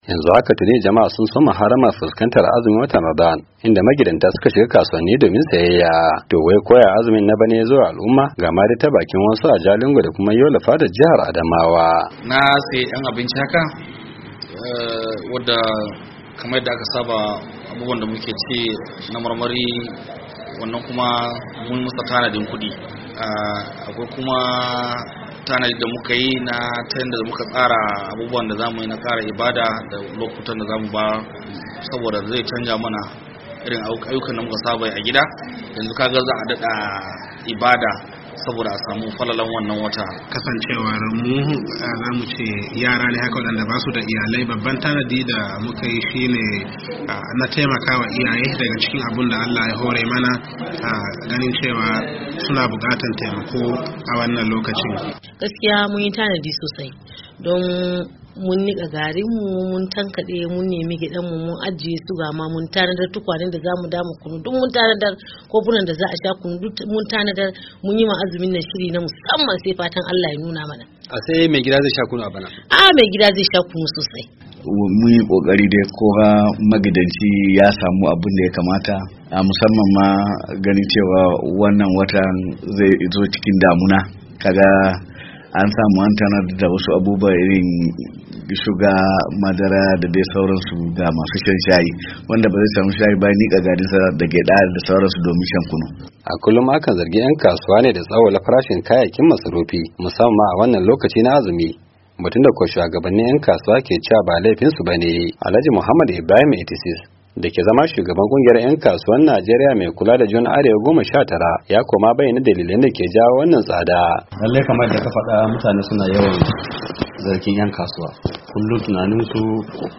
To ko yaya azumin na bana ya zo wa al’umma? ga ta bakin wasu a Jalingo da Yola wadanda suka ce sun shirya tsaf don shiga watan azumin.